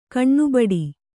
♪ kaṇṇubaḍi